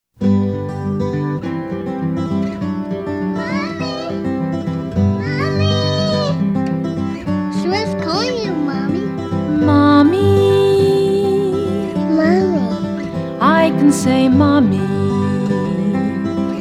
children's music